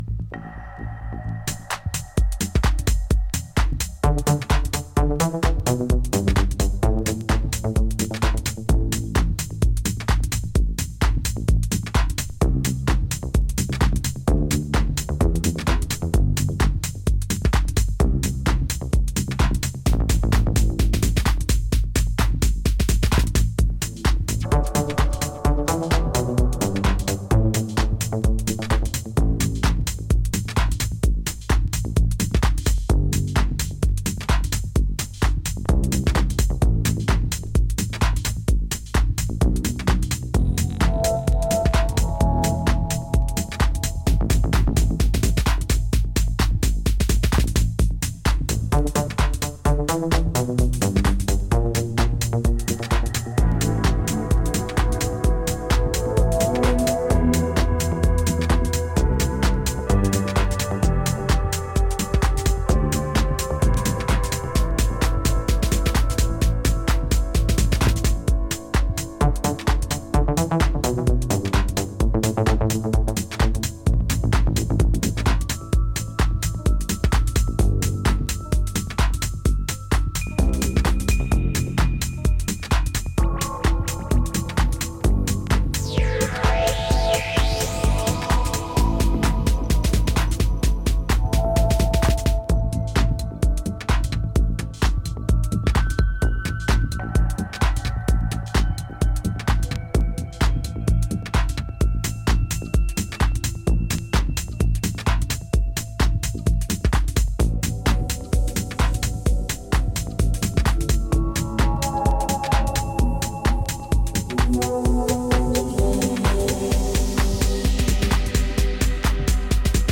幽玄かつドリーミーに揺らぐムードに艶やかなリフ等が静かな応酬を仕掛ける